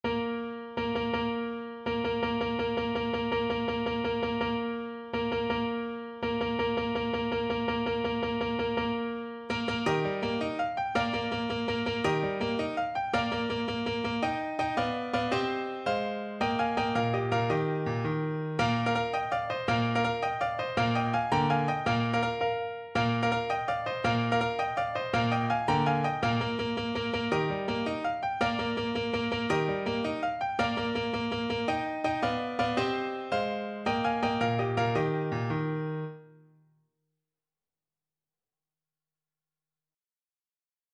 Classical Grétry, André La Victoire est a Nous (French Imperial March) Piano version
No parts available for this pieces as it is for solo piano.
6/8 (View more 6/8 Music)
With energy .=c.110
Eb major (Sounding Pitch) (View more Eb major Music for Piano )
Classical (View more Classical Piano Music)
la_victoire_et_a_nous_PNO.mp3